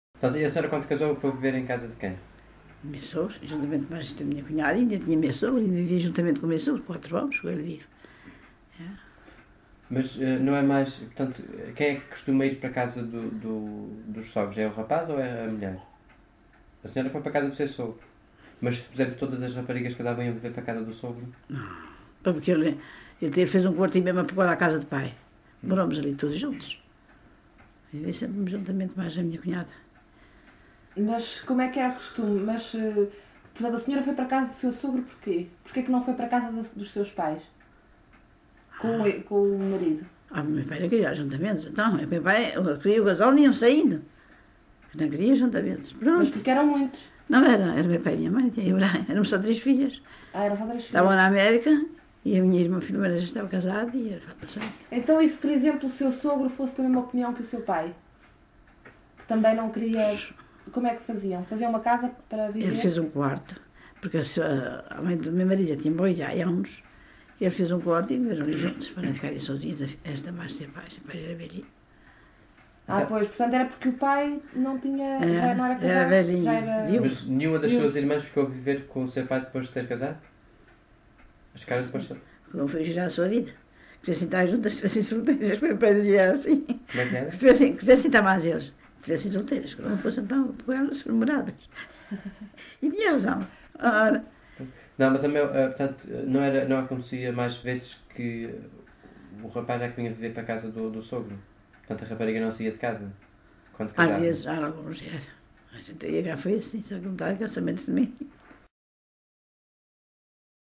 LocalidadeSanto Espírito (Vila do Porto, Ponta Delgada)